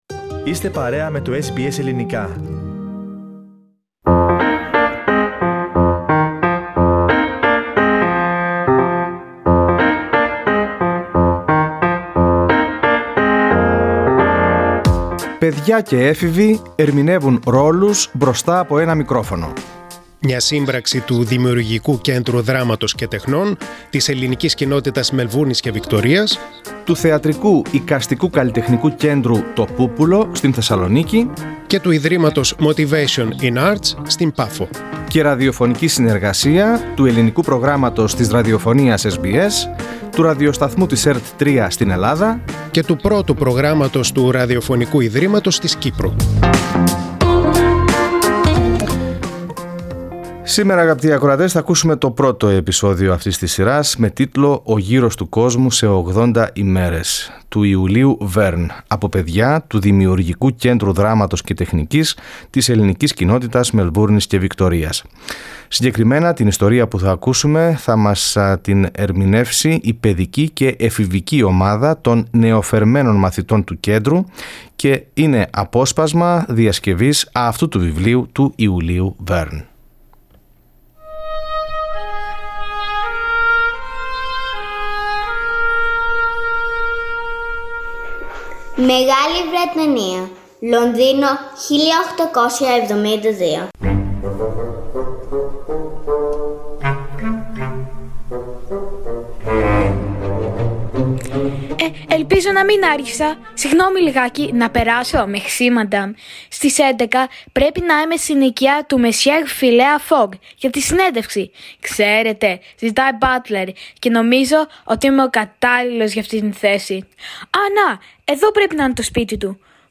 The radio-theatrical podcasts' recordings was done by the young students of Greek Community of Melbourne's Schools at their home assisted by educators & parents Source: Getty Images/skynesher